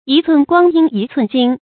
注音：ㄧ ㄘㄨㄣˋ ㄍㄨㄤ ㄧㄣ ㄧ ㄘㄨㄣˋ ㄐㄧㄣ
讀音讀法：